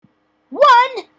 funny voices